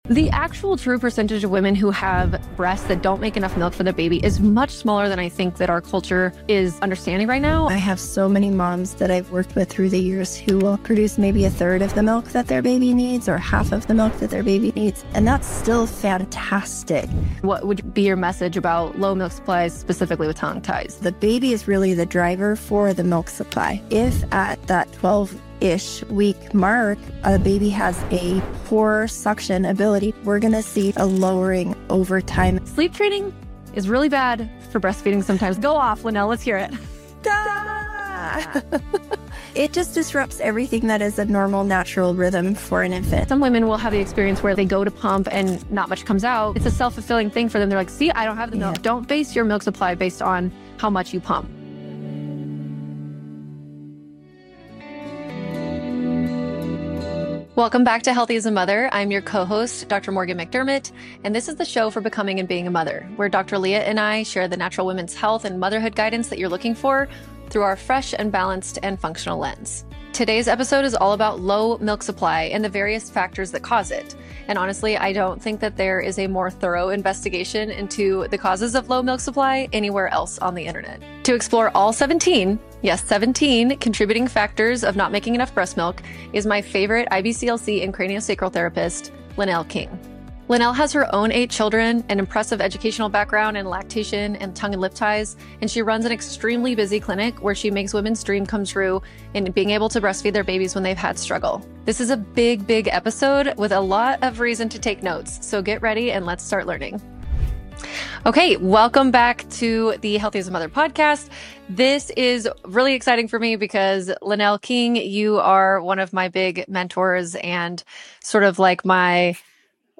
We open up the conversation on low milk supply.